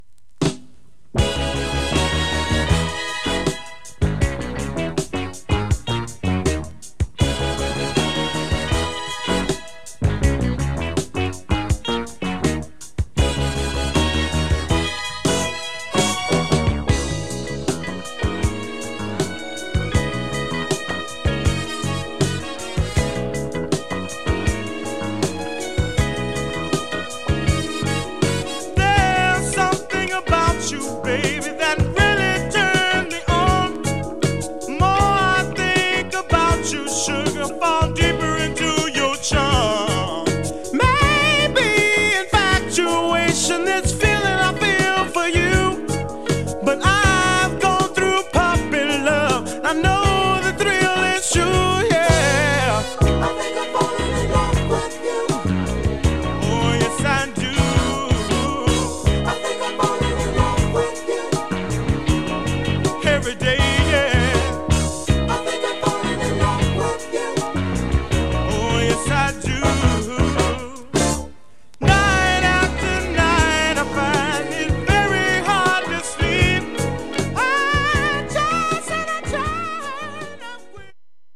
SOUL/FUNK